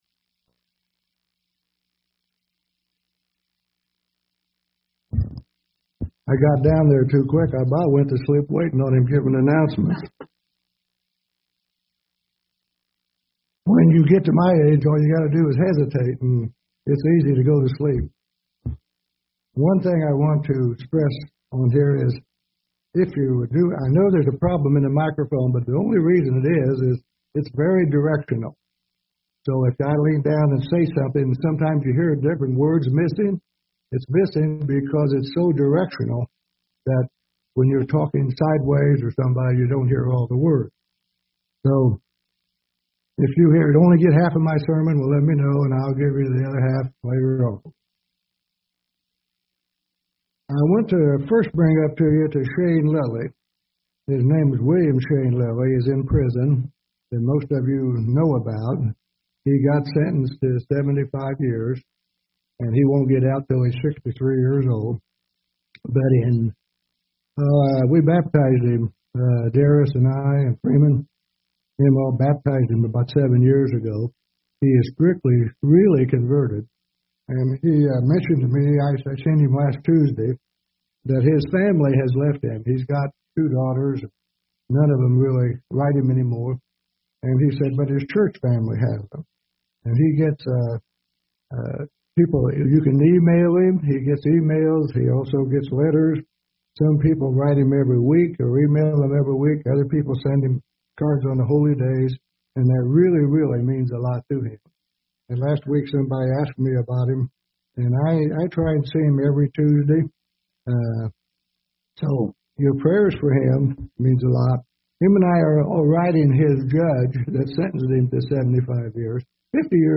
Sermons
Given in Indianapolis, IN Ft. Wayne, IN